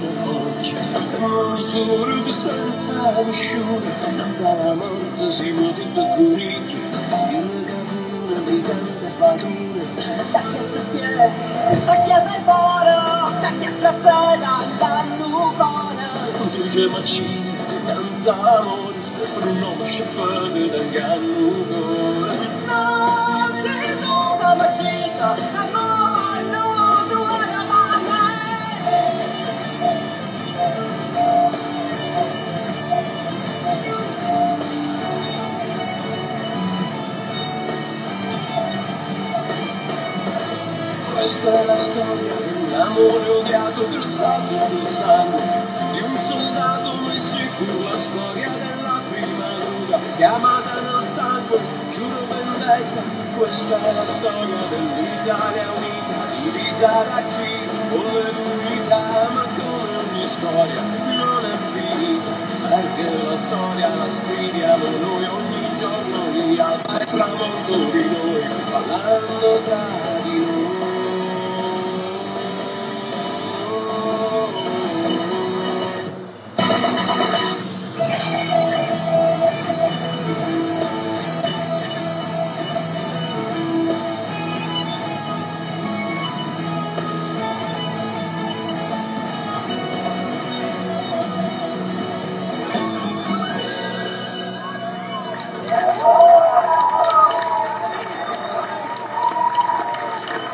Qui potete ascoltare (in bassa qualità)